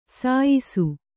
♪ sāyisu